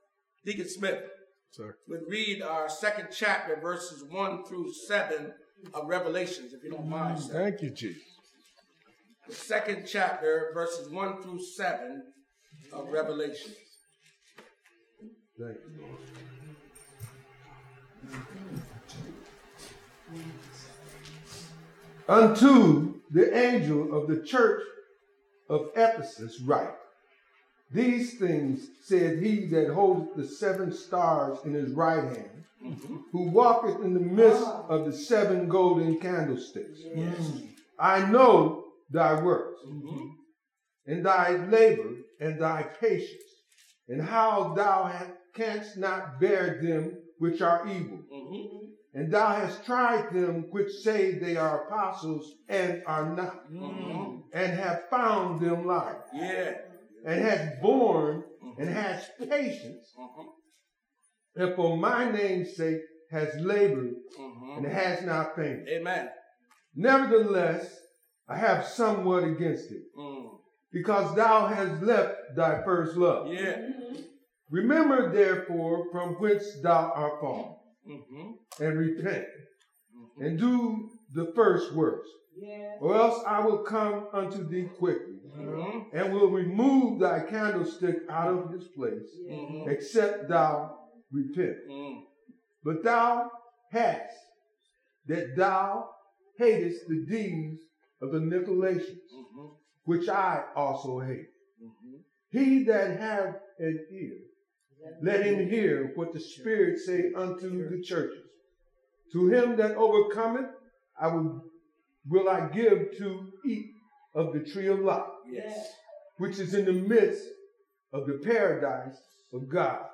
Sermons | Solid Rock Baptist Church - New Castle